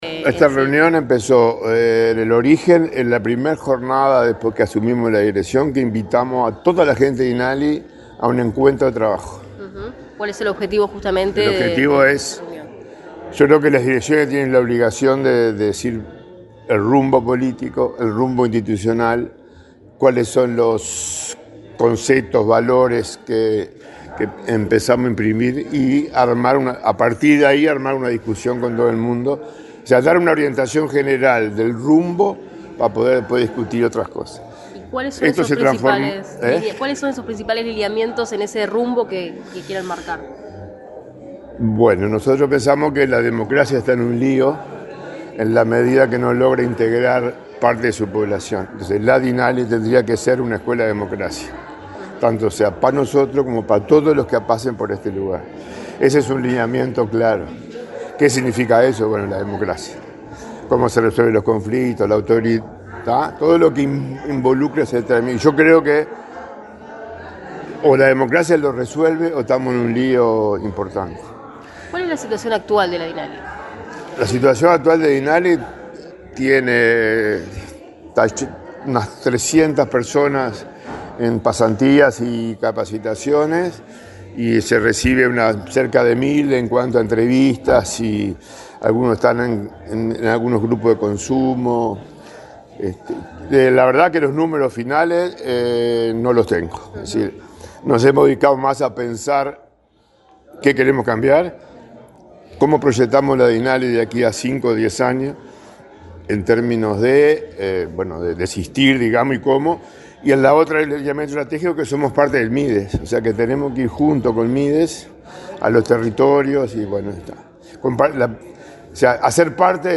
Declaraciones del director de la Dinali, Luis Parodi
El director de la Dirección Nacional de Apoyo al Liberado (Dinali), Luis Parodi, dialogó con la prensa, luego de participar del primer encuentro